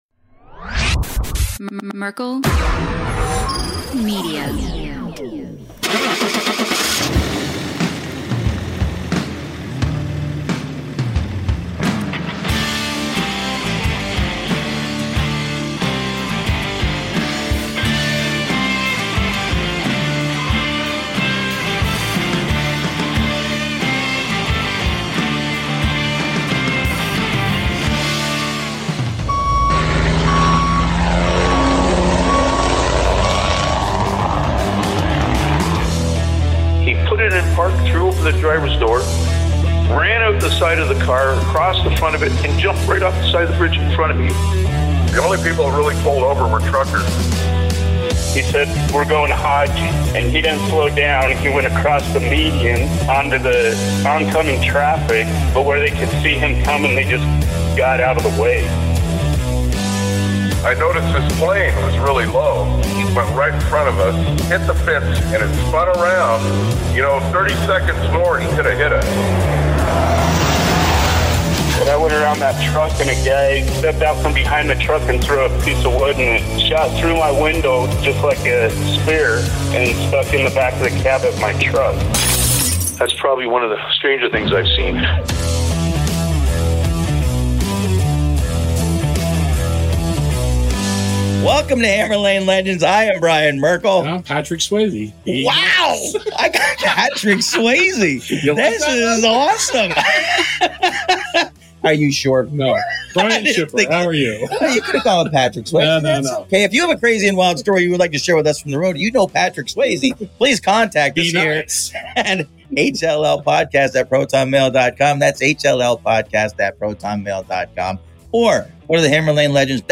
Amidst hearty laughs over quirky mishaps, she sheds light on the roadblocks women face in this male-dominated field, from sparse facilities to enduring stereotypes. They also swap tales of motoring through snow, their shared passion for quirky cars like the PT Cruiser, and the challenges of teaching the next generation to drive.